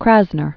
(krăznər), Lee 1908-1984.